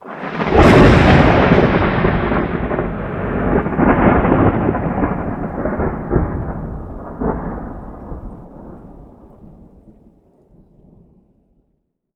THUNDER_Clap_Rumble_03_stereo.wav